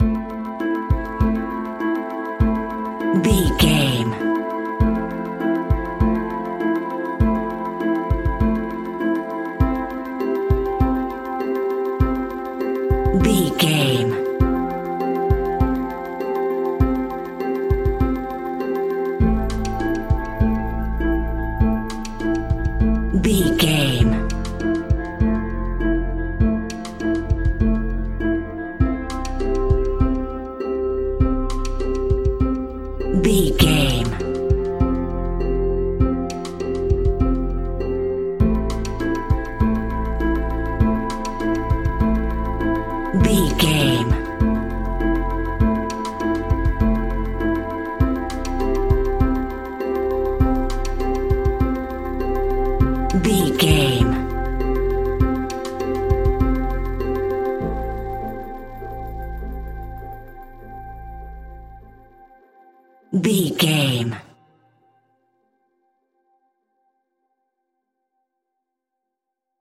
Ionian/Major
ominous
dark
haunting
eerie
synthesizer
drum machine
horror music
Horror Pads
Horror Synths